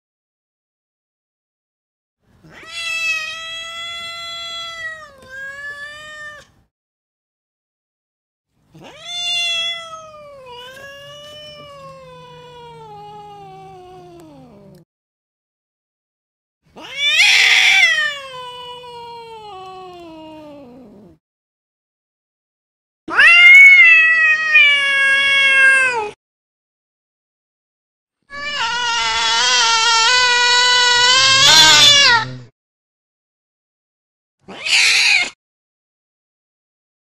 دانلود صدای میو میو گربه 4 از ساعد نیوز با لینک مستقیم و کیفیت بالا
جلوه های صوتی
برچسب: دانلود آهنگ های افکت صوتی انسان و موجودات زنده دانلود آلبوم میو میو گربه – انواع مختلف از افکت صوتی انسان و موجودات زنده